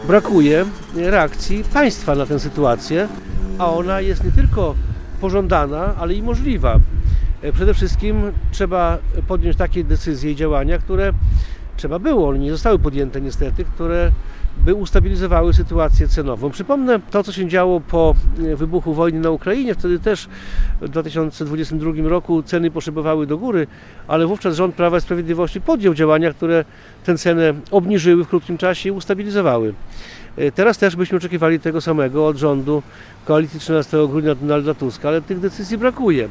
Podczas konferencji prasowej przy jednej ze stacji paliw w Suwałkach mówił o projekcie ustawy, który przygotowało Prawo i Sprawiedliwość. Wniosek PiS-u zakłada obniżenie VAT-u na paliwa z 23 na 8% i obniżenie akcyzy o 9-10%.